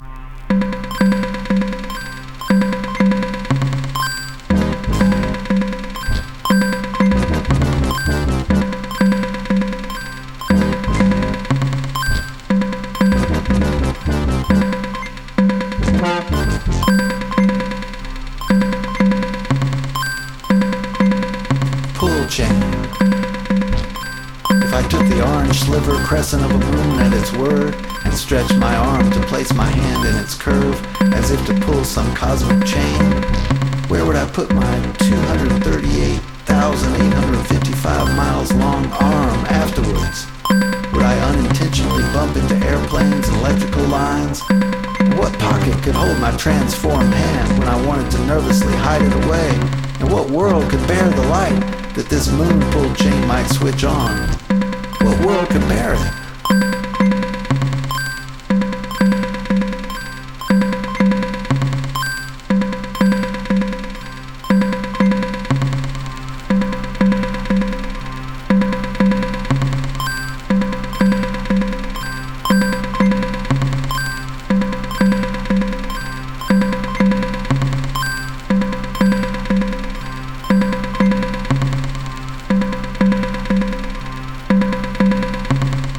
This bit of experimental whimsy owes a debt to the psychologists and philosophers who observe how our senses, such as sight, extend us beyond our physical selves and allow us to apprehend the world and beyond.
The vocal line is DNA-synthesized in a Laurie Anderson kind of style.